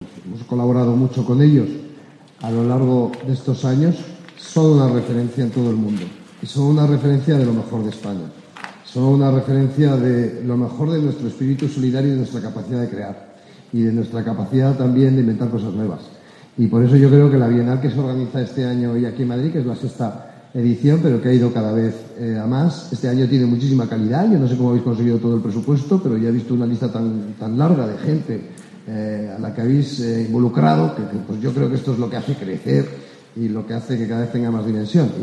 El ministro alabó la tarea global de la Fundación ONCE, con grandes logros en su trayectoria, como esta Bienal, cuyas cotas de calidad han aumentado -en su opinión- exponencialmente,